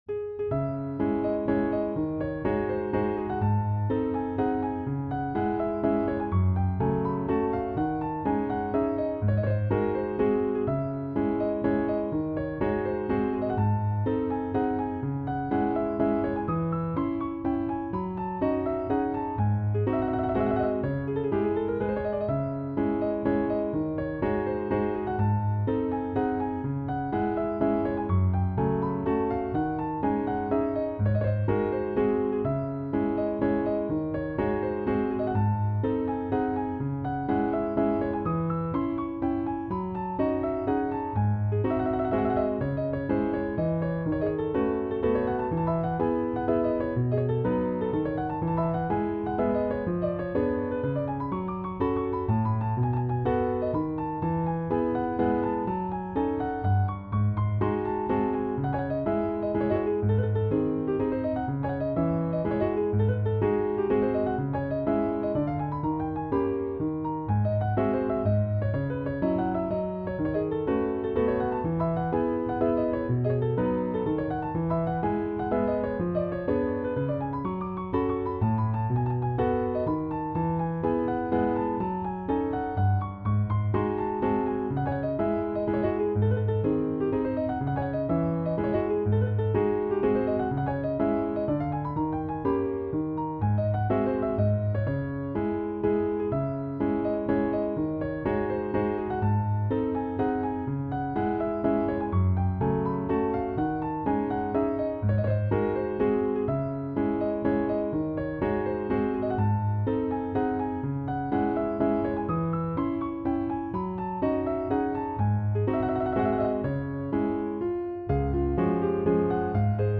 Waltz Simplex in C# Major - Piano Music, Solo Keyboard - Young Composers Music Forum
A simple Chopin-esque waltz inspired by Chopin's Op.70 No.3 and Op.69 No.2.Lemme know what you think!